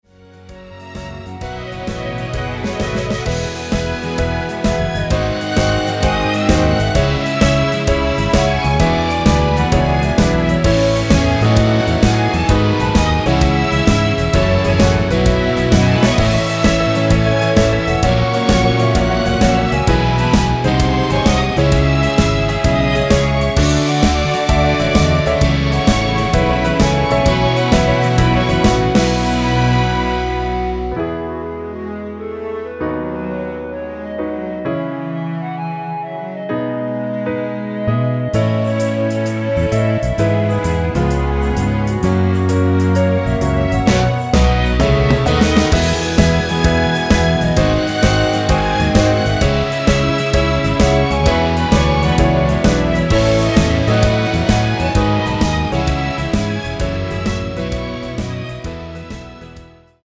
전주 없는 곡이라 전주 2마디 만들어 놓았습니다.
엔딩이 페이드 아웃이라 라이브 하시기 편하게 엔딩을 만들어 놓았습니다
1절후 2절 없이 후렴으로 진행 됩니다.(본문 가사 참조)
Db
멜로디 MR이라고 합니다.
앞부분30초, 뒷부분30초씩 편집해서 올려 드리고 있습니다.
중간에 음이 끈어지고 다시 나오는 이유는